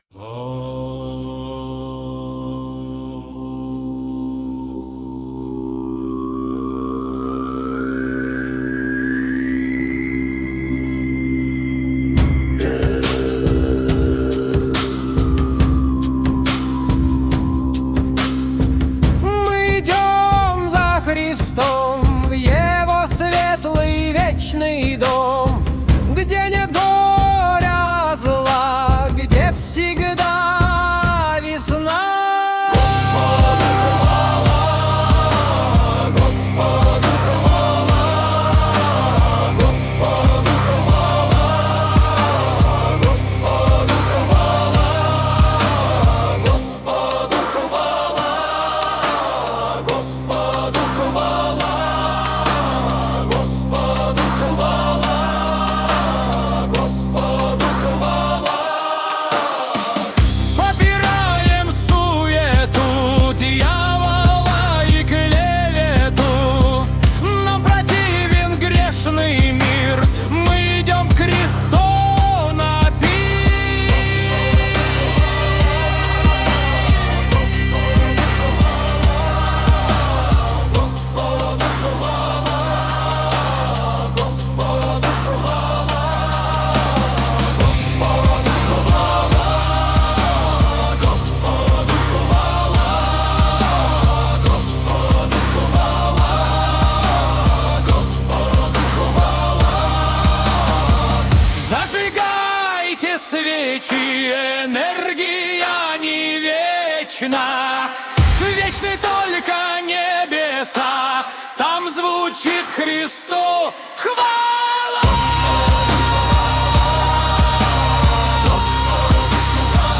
Нейросеть поёт Христу.
Представленные ниже песни были созданы с помощью нейронной сети на основе наших стихов